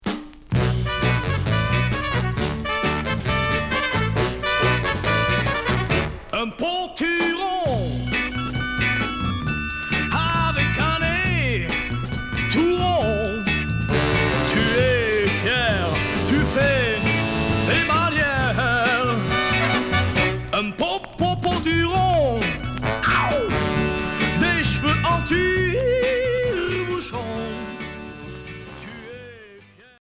Chanteur 60's Unique EP retour à l'accueil